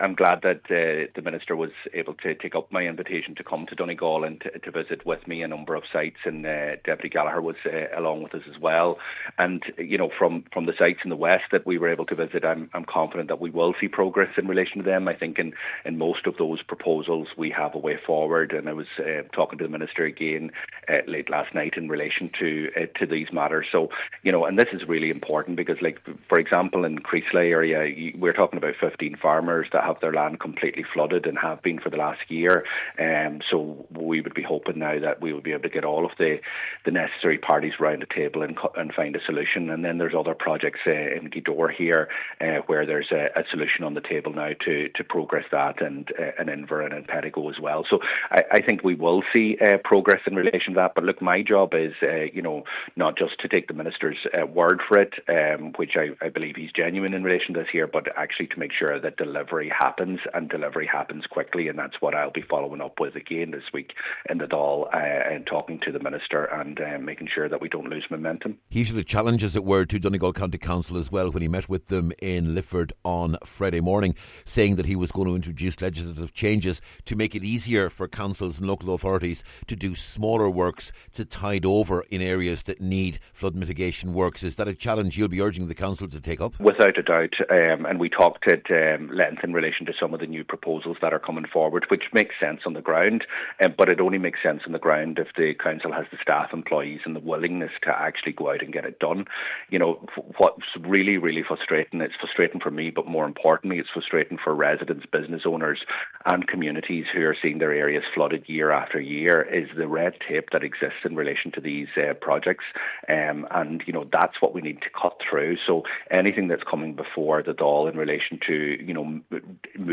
Deputy Pearse Doherty was speaking he and Deputy Pat the Cope Gallagher visited a number of affected areas in south and west Donegal with Minister Moran, telling him that there are potential solutions available.